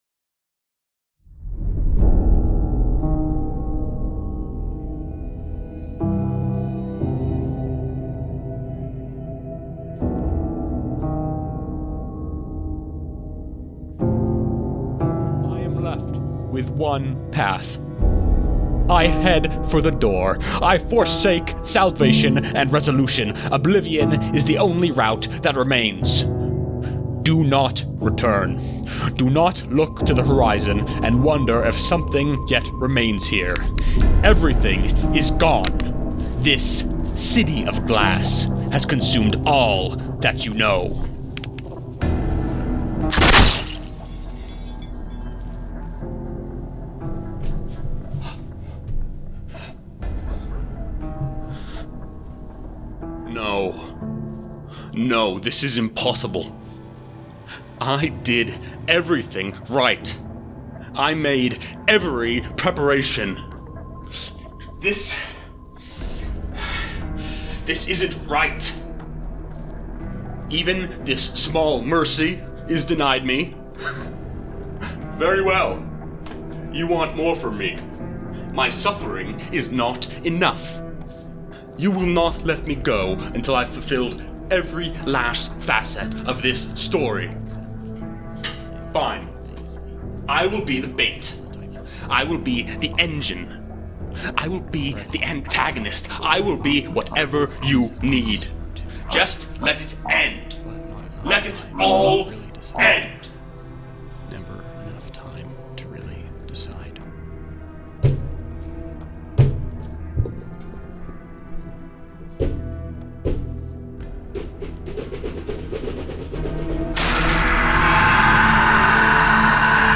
Lifestyle Games and Gambling Audio Drama Calamity Cascade Content provided by All That You Know - Actual Play Series.